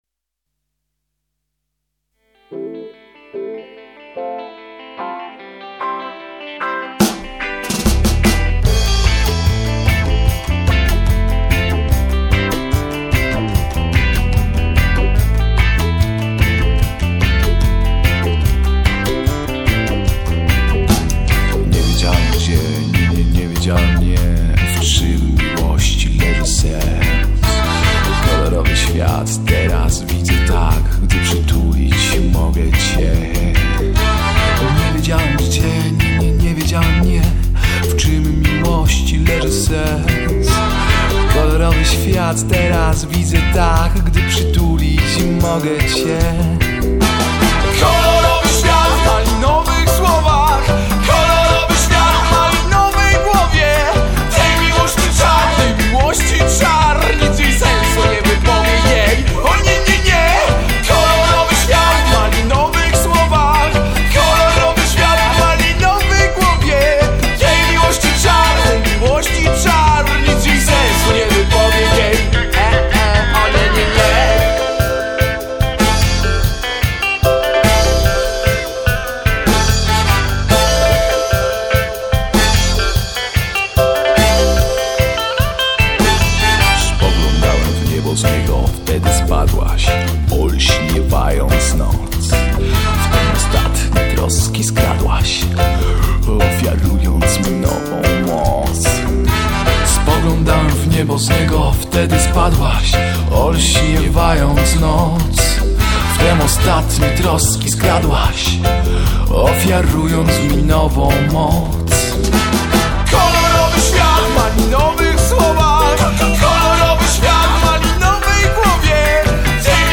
Gatunek: Rock, Reggae, Funk